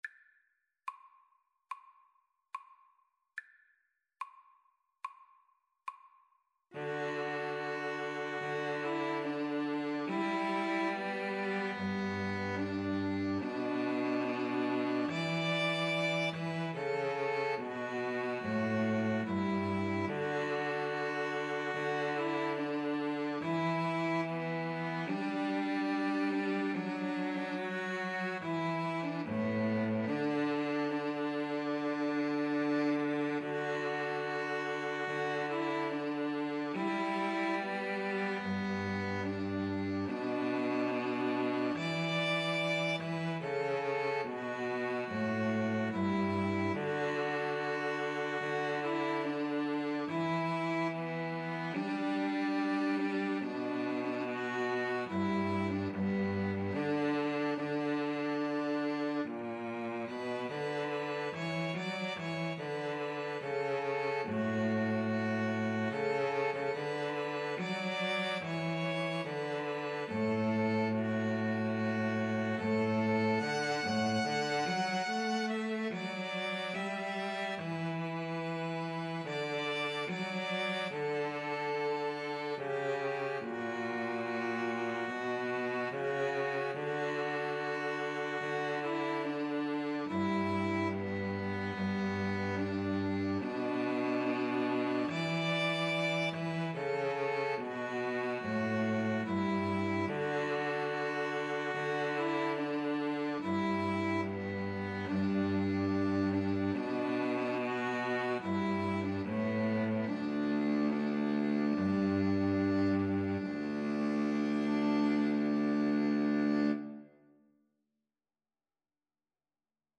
ViolinViolaCello
4/4 (View more 4/4 Music)
D major (Sounding Pitch) (View more D major Music for String trio )
Andante =c.72
String trio  (View more Easy String trio Music)
Traditional (View more Traditional String trio Music)